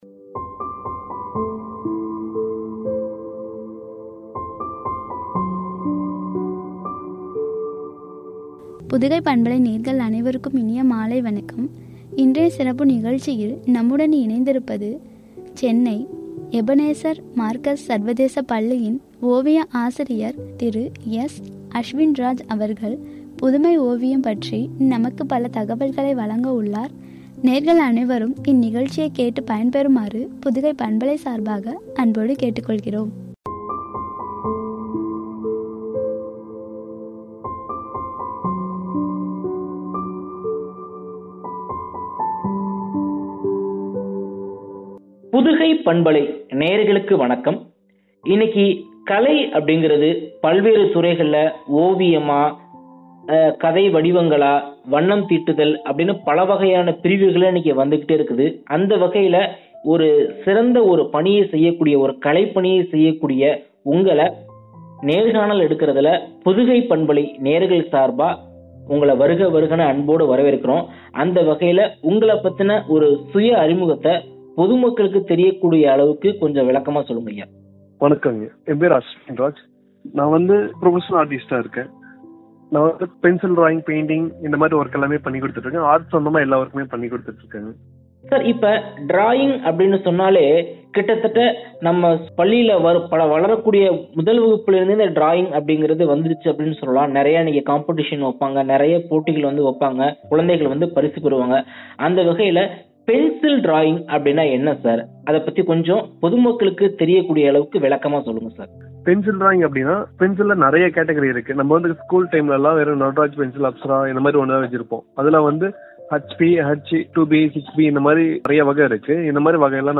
“புதுமை ஓவியம்” எனும் தலைப்பில் வழங்கிய உரையாடல்.